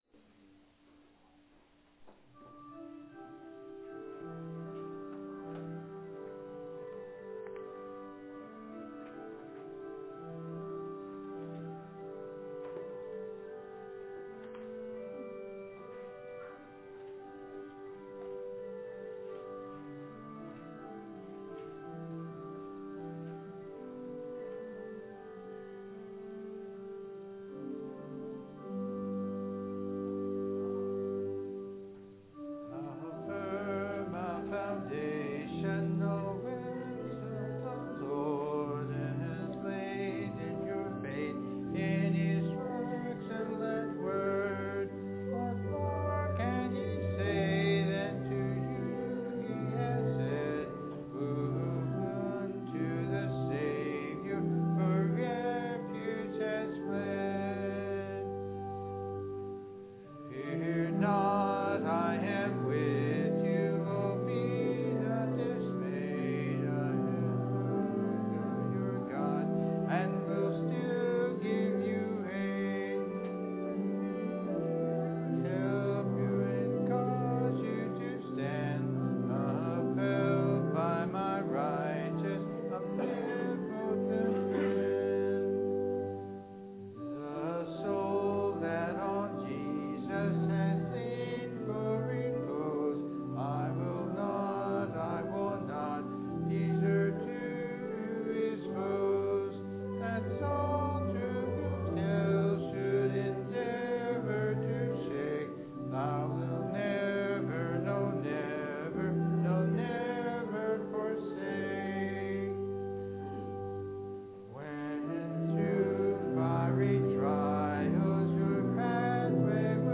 Audio-Service-for-the-Celebration-of-All-Saints-Day-1.mp3